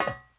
metalFootStep04.wav